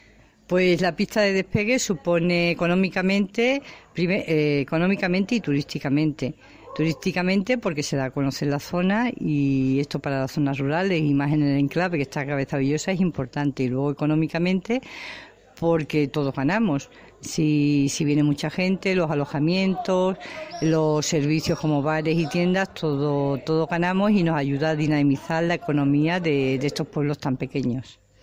Alcaldesa-Cabezabellosa.mp3